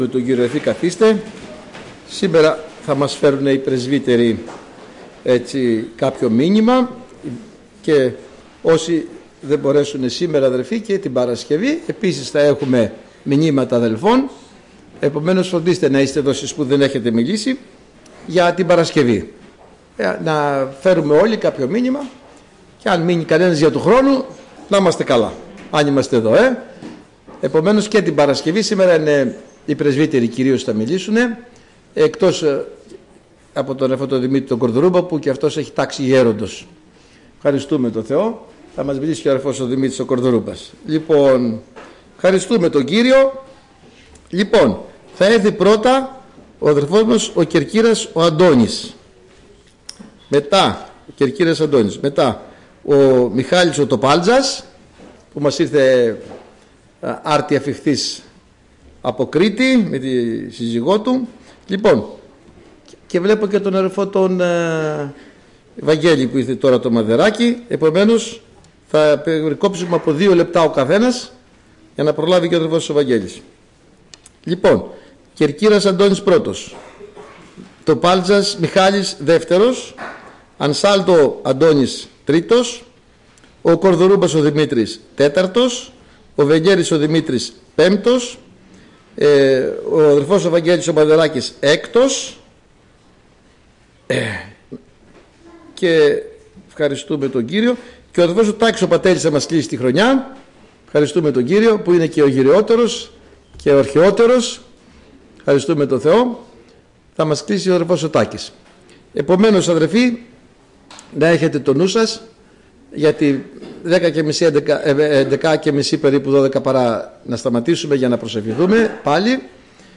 Πρωτοχρονιάτικη Σύναξη: Μηνύματα Αδερφών για το Νέο Έτος Ομιλητής: Διάφοροι Ομιλητές Λεπτομέρειες Σειρά: Μηνύματα Ημερομηνία: Τετάρτη, 31 Δεκεμβρίου 2025 Εμφανίσεις: 10 Λήψη ήχου ( 29.68 MB ) Λήψη βίντεο